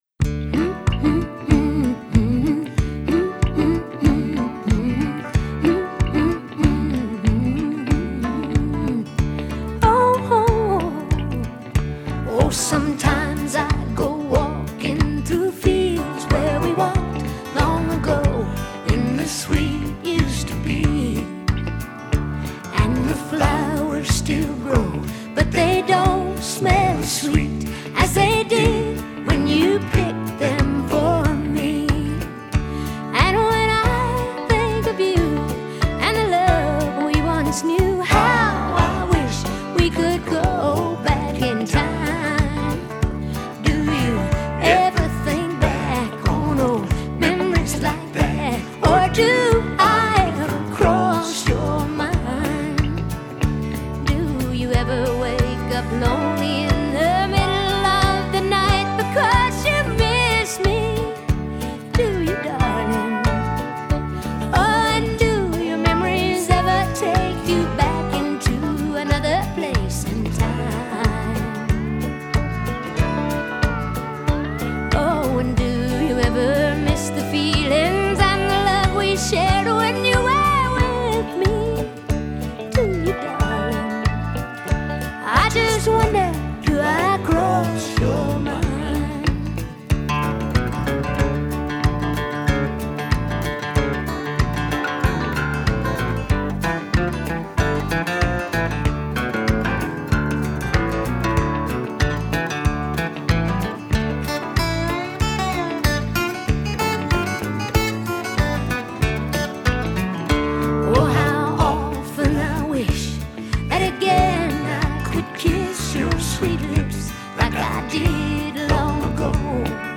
lilting
the early ’80s gem